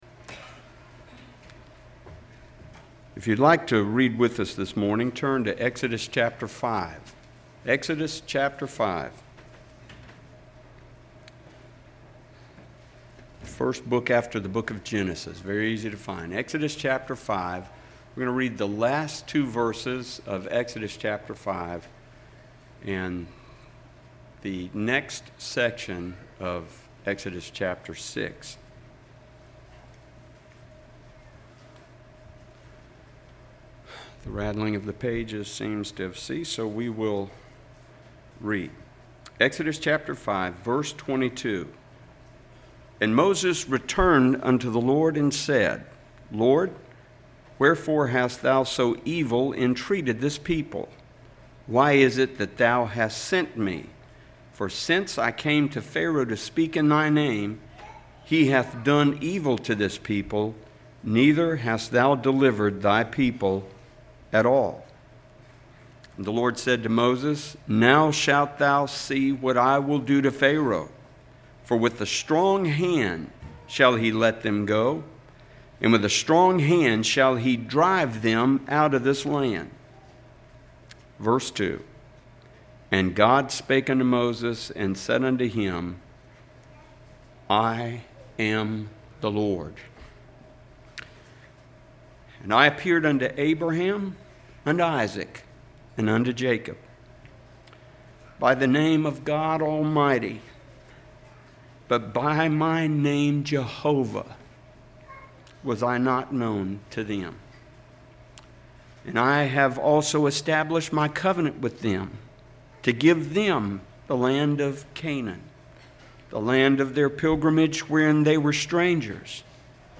07/22/18 Sunday Morning